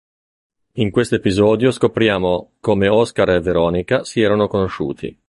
e‧pi‧sò‧dio
/e.piˈzɔ.djo/